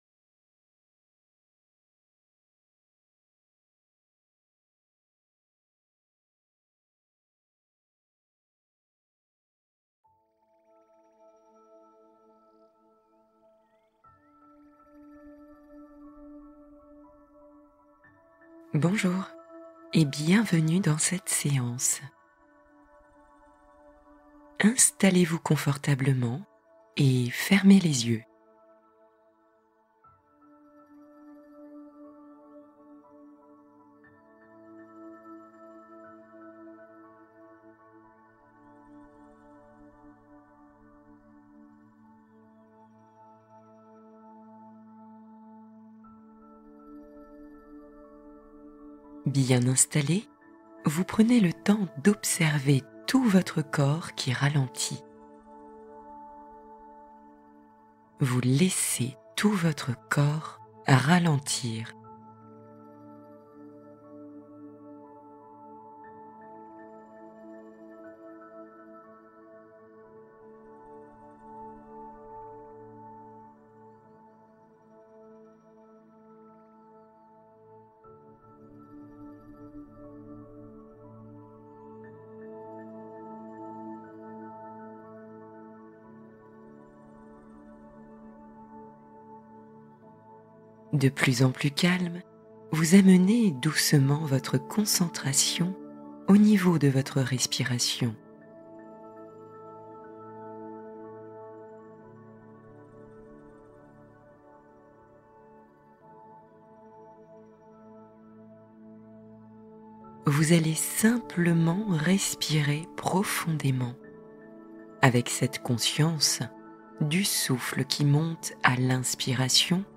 Le trésor — Histoire apaisante pour se recentrer